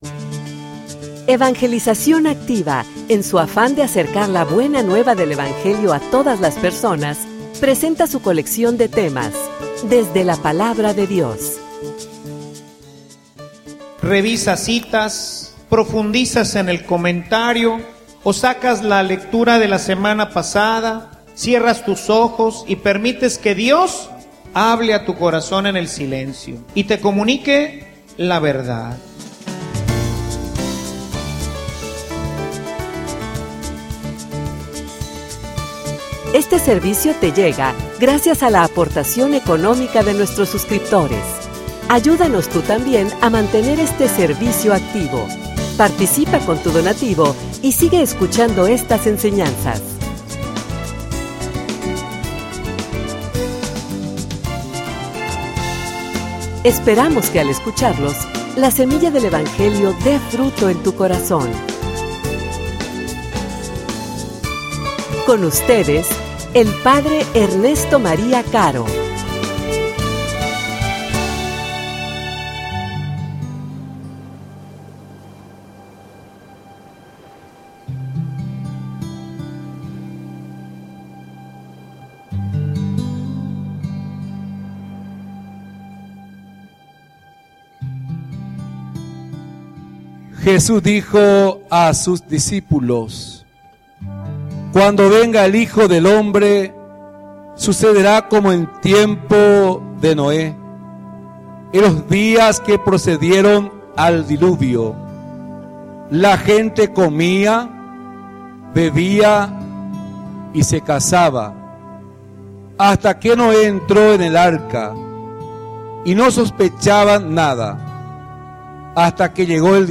homilia_El_camino_de_la_redencion.mp3